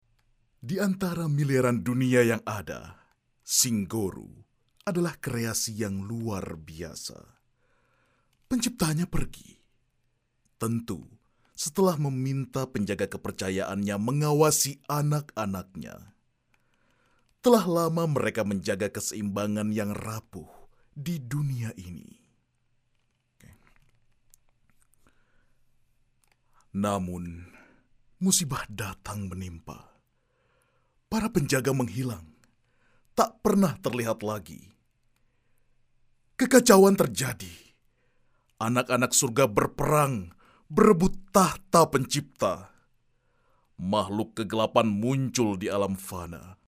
Commercieel, Stoer, Veelzijdig, Volwassen, Warm